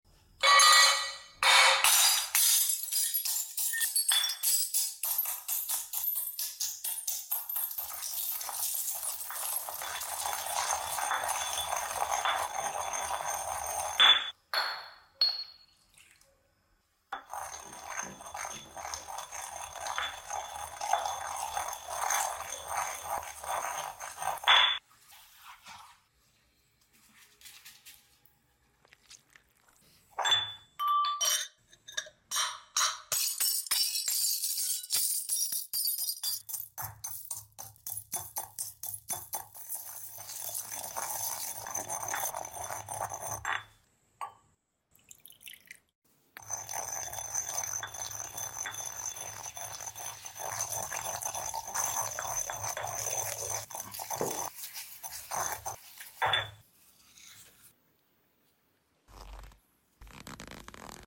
Upload By ASMR videos
Oddlysatisfying crushing glass into paste